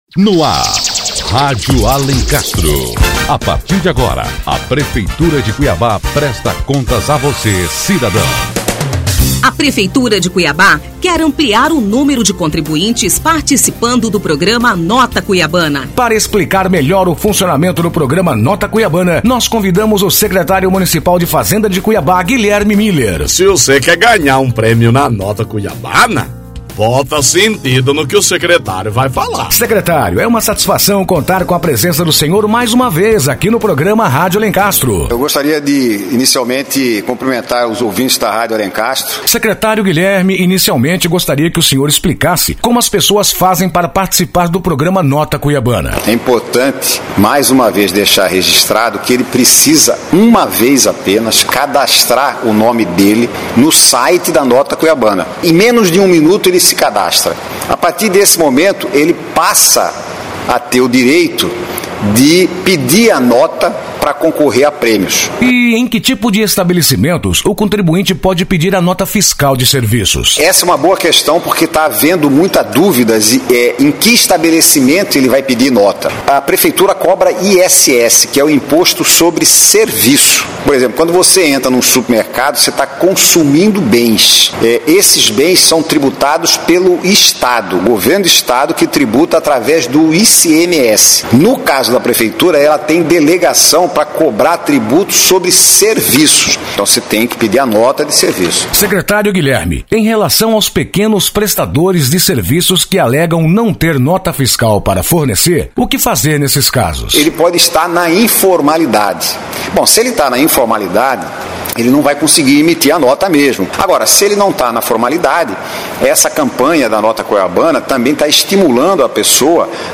O Secretário de Fazenda, Guilheme Muller, é entrevistado e esclarece as principais dúvidas sobre a Nota Cuiabana.